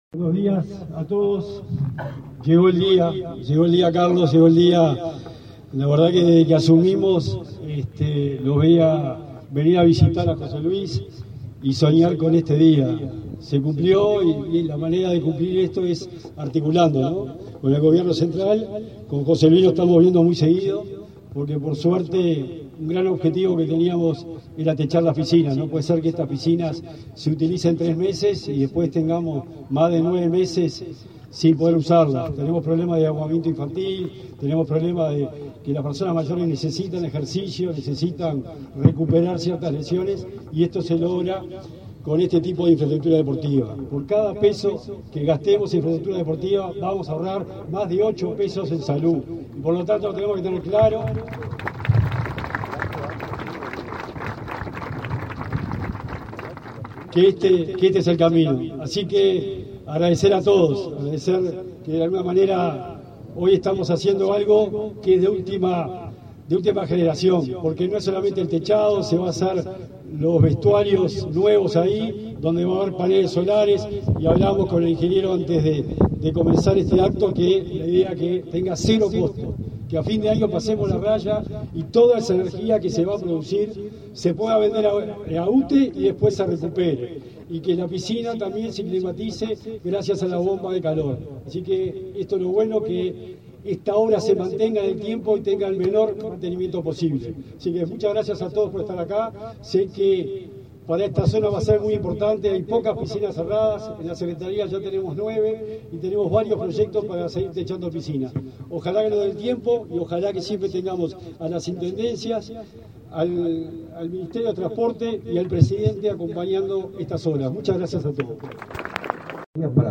Conferencia de prensa por acuerdo para concreción de obras en piscina de plaza de deportes en Las Piedras
El Ministerio de Transporte y Obras Públicas (MTOP) firmó un convenio con la Secretaría Nacional del Deporte (SND), la Intendencia de Canelones, el Municipio de Las Piedras, el Club de Leones de esa localidad y la Asociación Civil Pro Plaza de Deportes 18 de Mayo, para la concreción de obras de cerramiento y climatización de la piscina ubicada en el referido espacio. En el evento, participaron los titulares del MTOP, José Luis Falero, y la SND, Sebastián Bauzá.